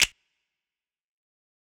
YM - Reverb Snap 4.wav